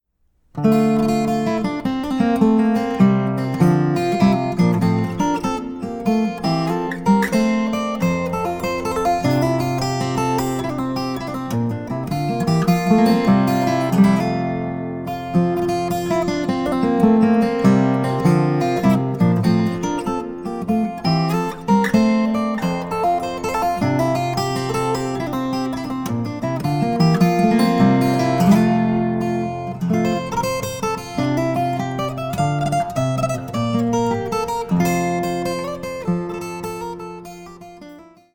My solo guitar album
traditional Irish